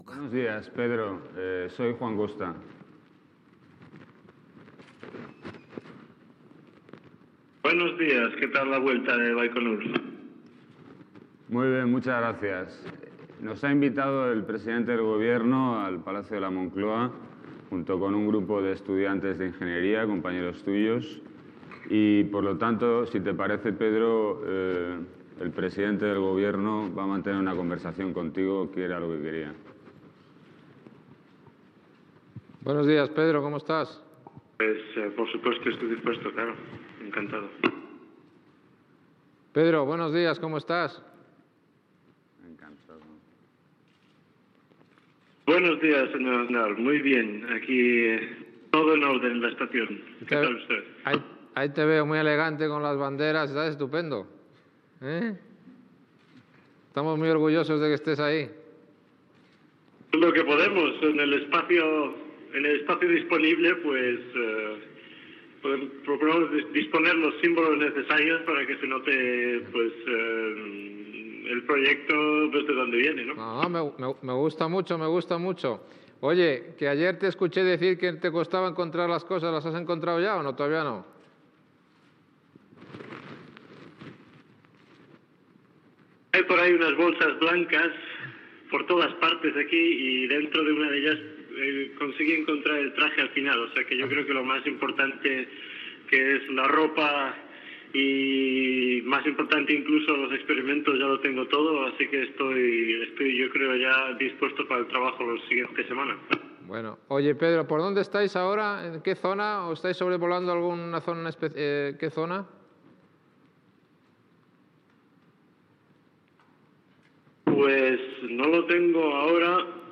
L'astronauta espanyol Pedro Duque, de l'Agència Espacial Europea, parla, des de l'Estació Espacial Internacional (ISS), amb el ministre de ciència Juan Costa i el president del govern espanyol José María Aznar.
Informatiu
Extret del programa "El sonido de la historia", emès per Radio 5 Todo Noticias el 20 d'octubre de 2012